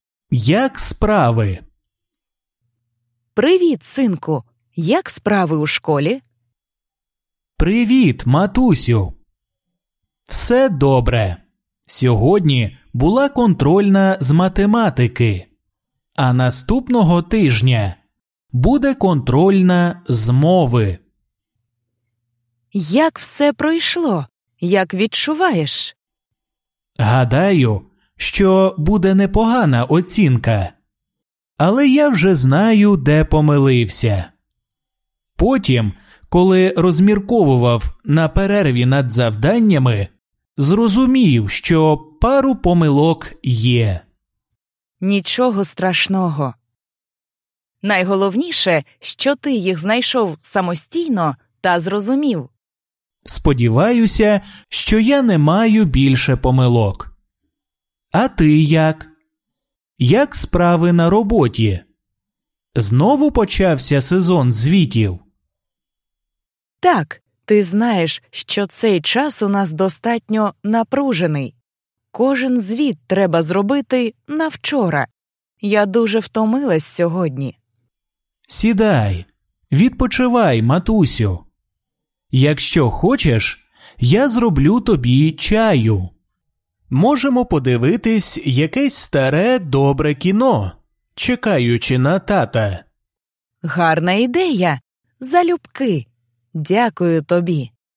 Dialogues with audio